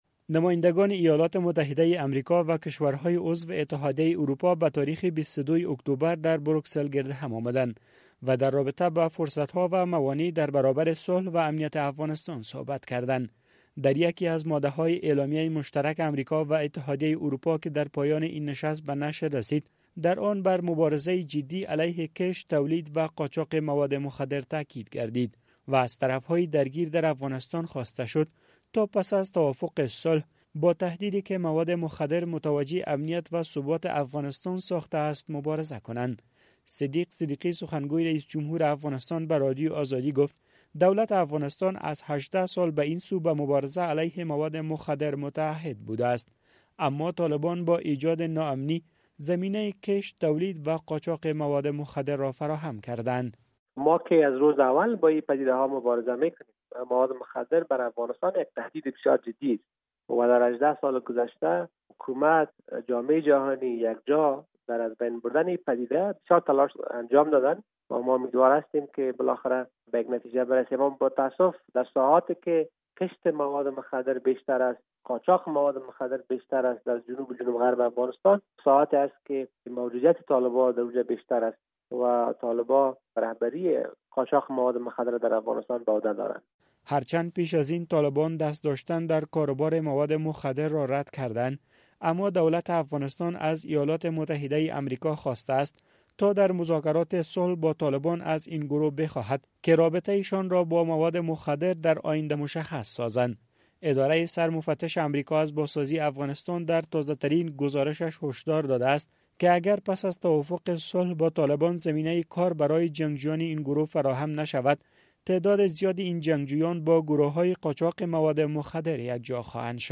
گزارش